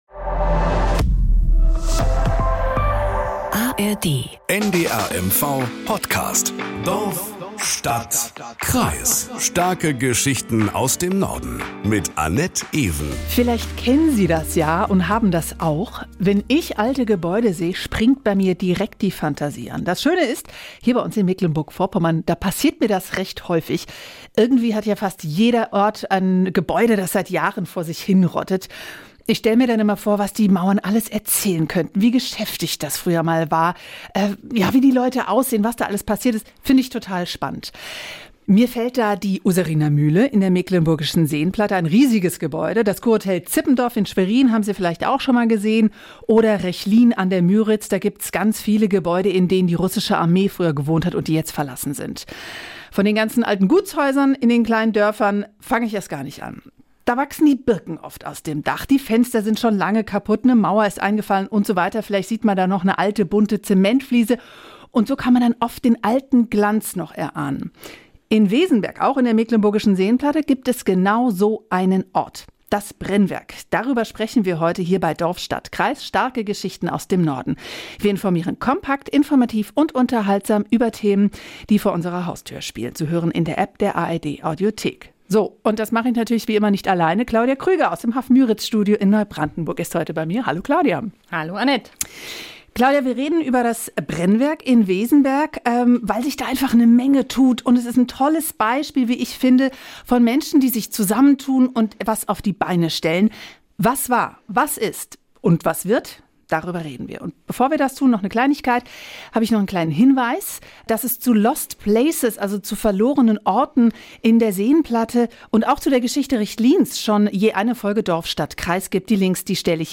Nachrichten aus Mecklenburg-Vorpommern - 25.05.2024